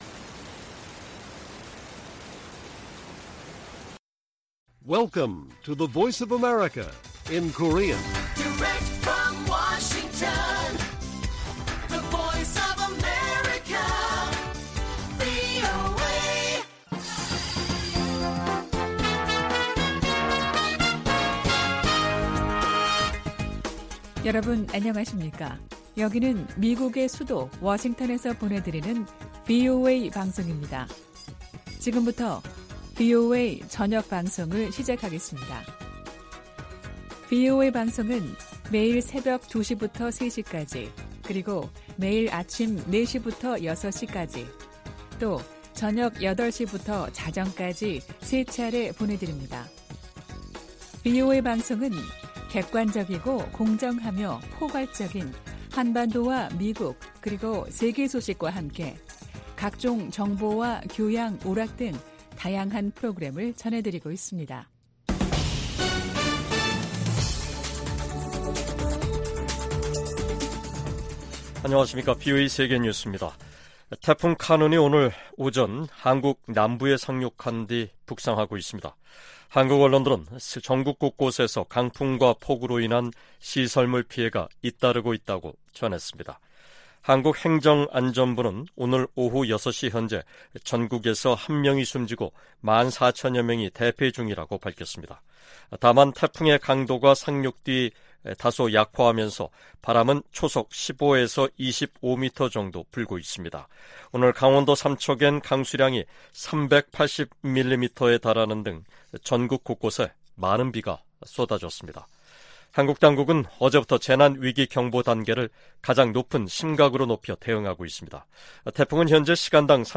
VOA 한국어 간판 뉴스 프로그램 '뉴스 투데이', 2023년 8월 10일 1부 방송입니다. 조 바이든 미국 대통령이 다음 주 열리는 미한일 정상회의에서 역사적인 논의를 고대하고 있다고 백악관 고위관리가 밝혔습니다. 미 국무부는 북한의 개성공단 무단 가동 정황과 관련해 기존 제재를 계속 이행할 것이라고 밝혔습니다. 김정은 북한 국무위원장이 '을지프리덤실드' 미한 연합연습을 앞두고 노동당 중앙군사위원회 확대회의를 열어 '공세적 전쟁 준비'를 강조했습니다.